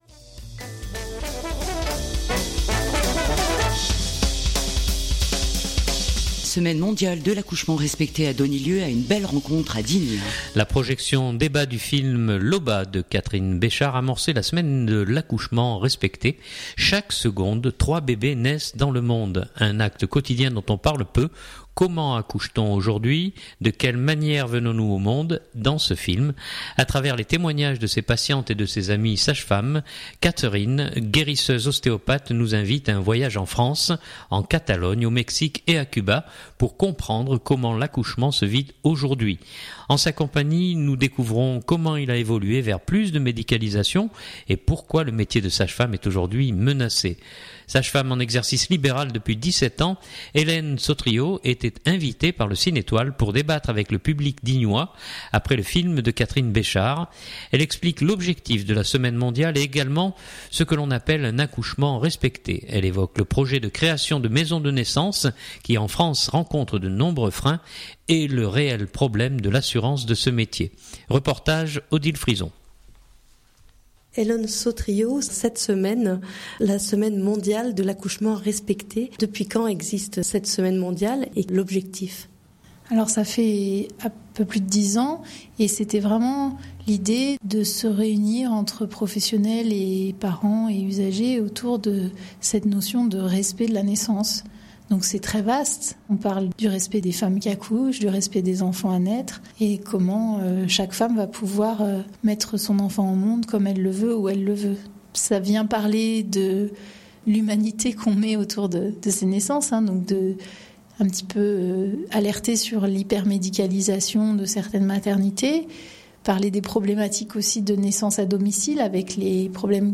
Elle explique l’objectif de la semaine mondiale et également ce que l’on appelle un accouchement respecté. Elle évoque le projet de création de maisons de naissance qui en France rencontre de nombreux freins, et le réel problème de l’assurance de ce métier. Reportage